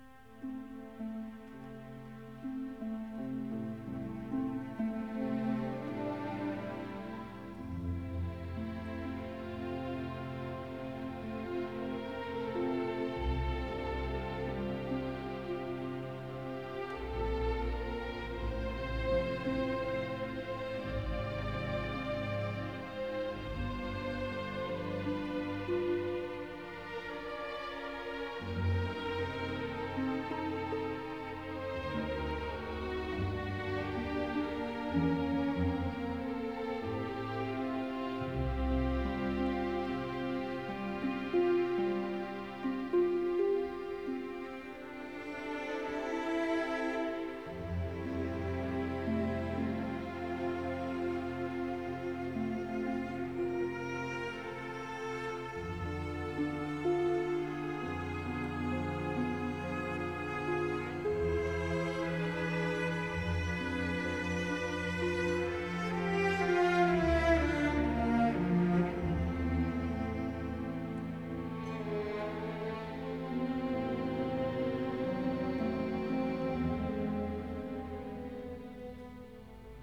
C sharp minor